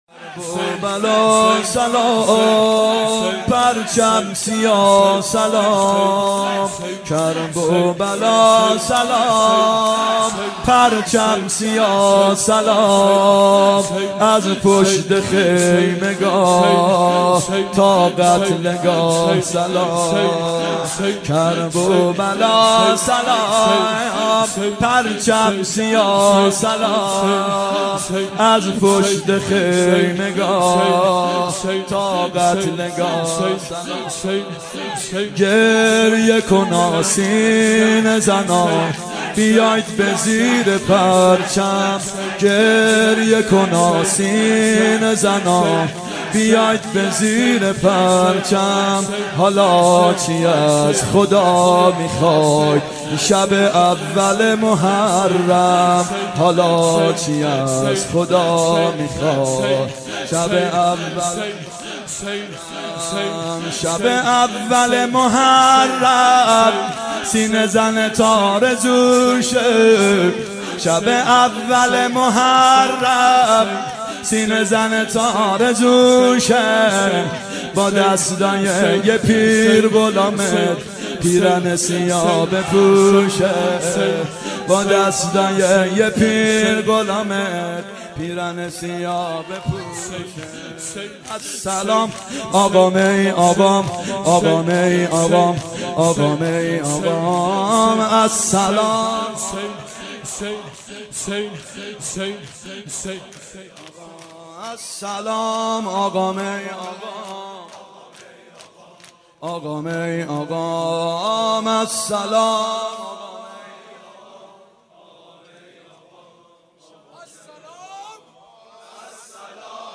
محرم 91 شب اول شور (کربلا سلام پرچم سیا سلام
محرم 91 ( هیأت یامهدی عج)